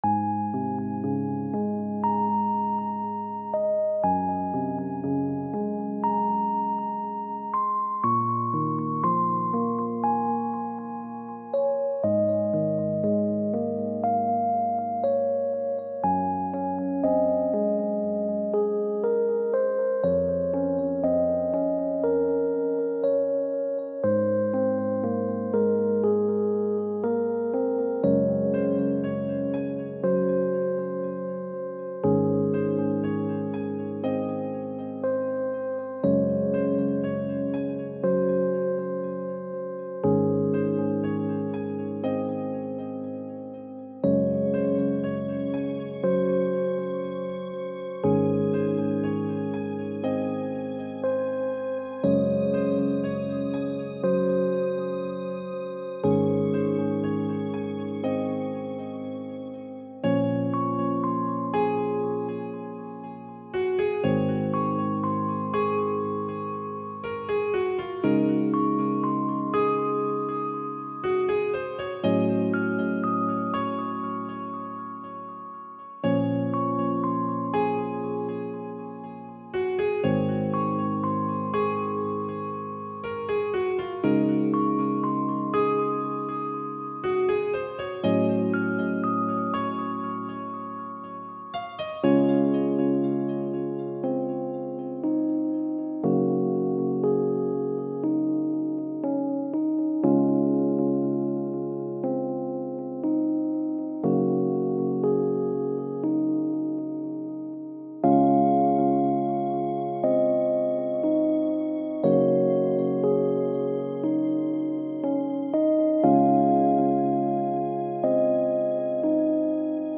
somber_moment.mp3